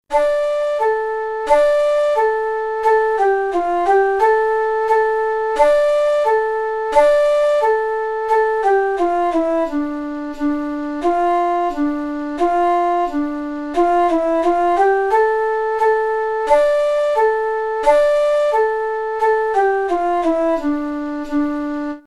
Popular de Hungría, flautas a dos voces y xilófonos.